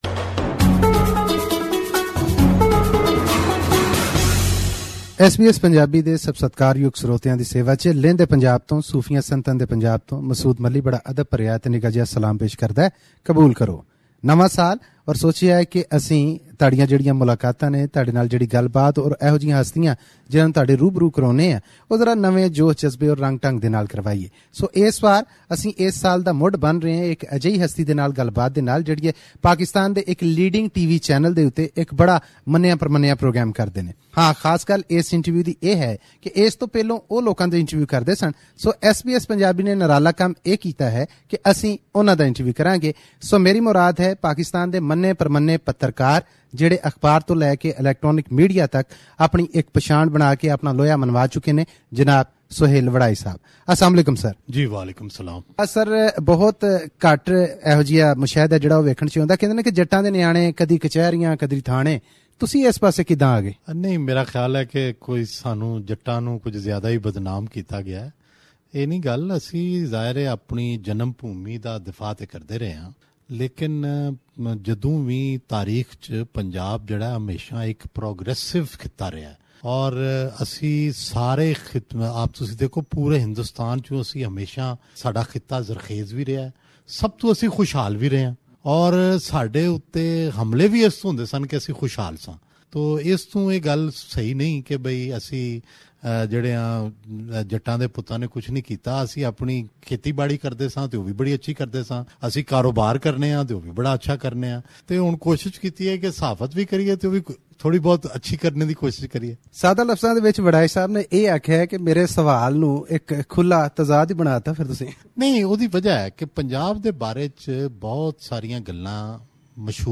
Interview with prominent Pakistani journalist Mr Sohail Warraich